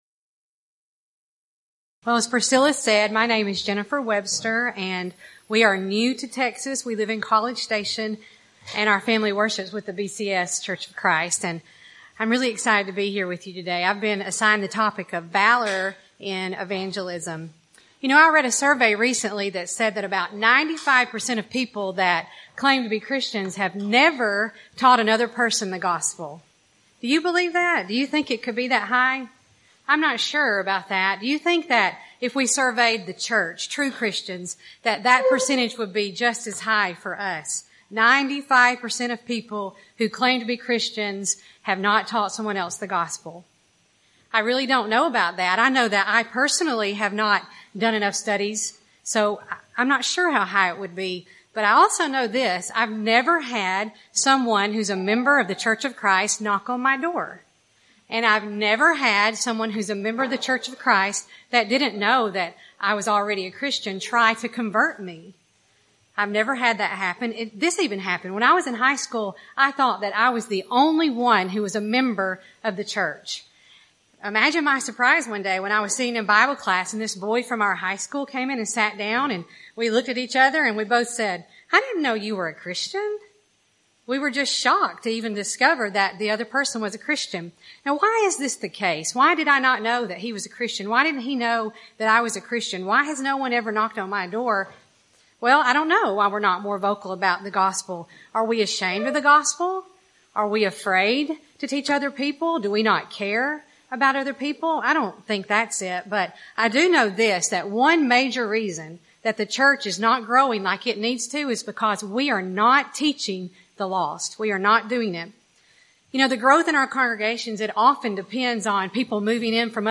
Event: 1st Annual Women of Valor Retreat
Ladies Sessions